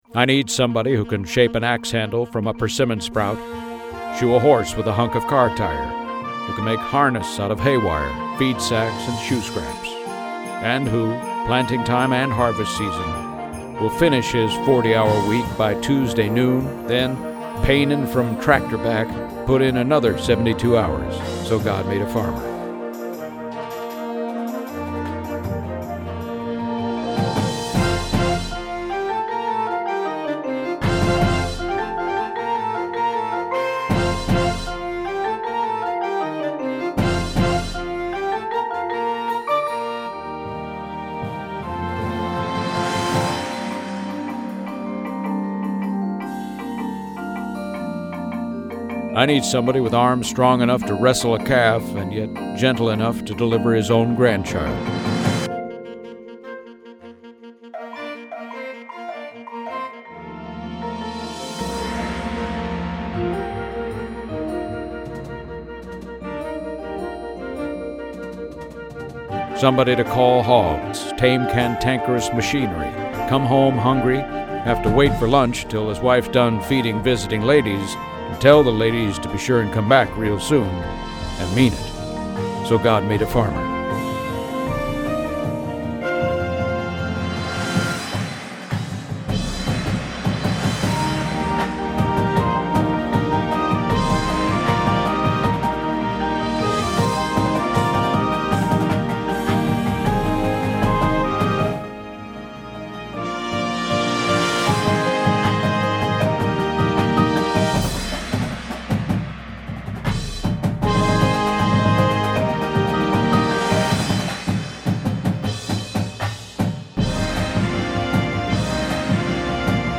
• Flute
• Clarinet 1, 2
• Alto Sax
• Trumpet 1, 2
• Horn in F
• Low Brass 1, 2
• Tuba
• Snare Drum
• Synthesizer
• Marimba 1, 2
• Glockenspiel